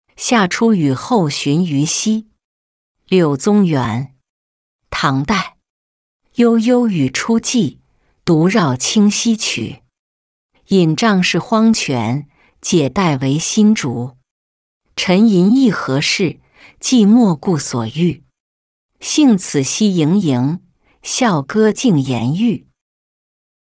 夏初雨后寻愚溪-音频朗读